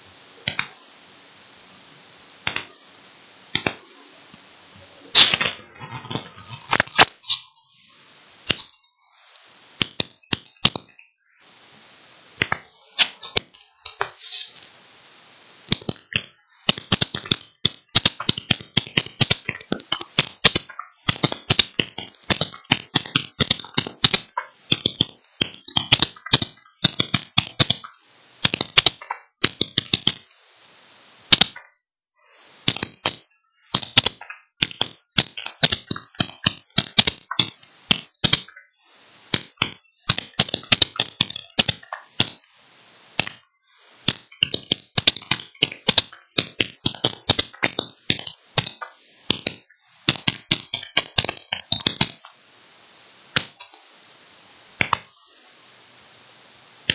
The feature below is to see how ultrasound can be used to listen into what you are typing on your keyboard – keyboard logging using an ultrasound microphone.
keyboard logger and ultrasound microphone 22 oct 2015
So when you listen to the keyboard using an ultrasound mic, you can see/hear that each of the strokes have a personality of their own which is distinct and can be recognized either by listening to the strokes or by translating them using an algorithm.